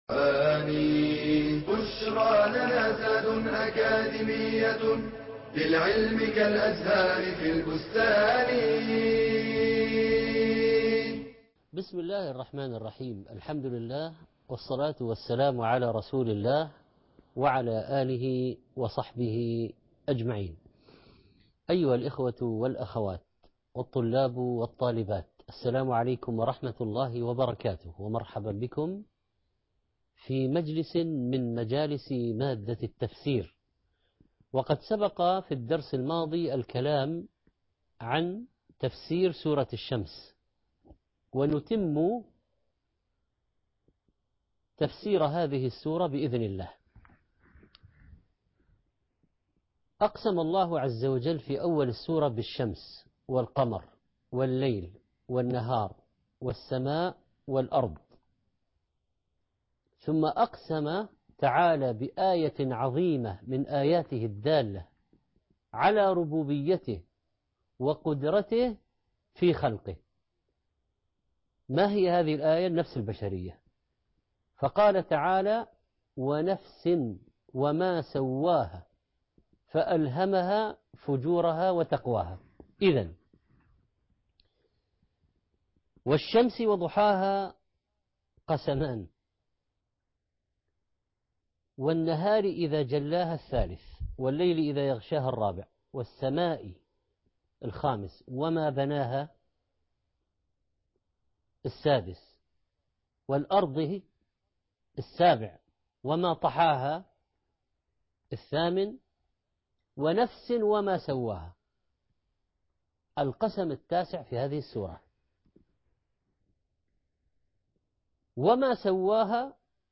المحاضرة الثالث والعشرون- سورة الليل ( 24/4/2017 ) التفسير - الشيخ محمد صالح المنجد